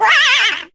yoshi_underwater_suffocation.ogg